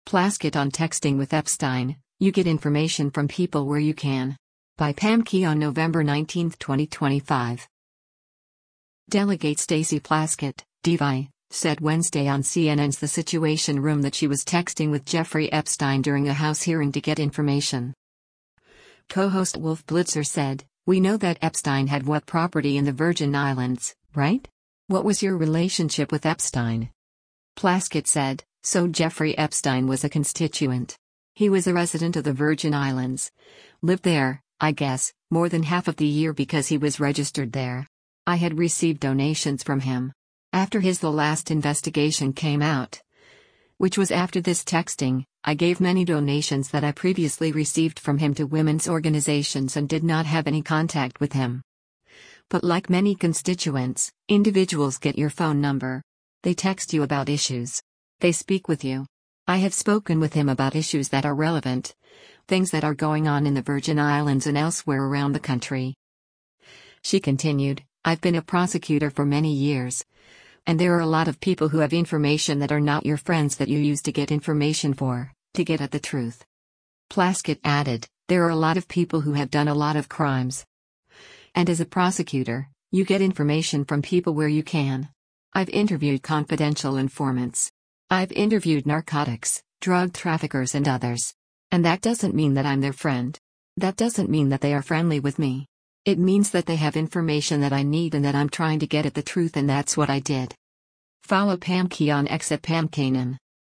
Delegate Stacey Plaskett (D-VI) said Wednesday on CNN’s “The Situation Room” that she was texting with Jeffrey Epstein during a House hearing to “get information.”
Co-host Wolf Blitzer said, “We know that Epstein had what property in the Virgin Islands, right? What was your relationship with Epstein?”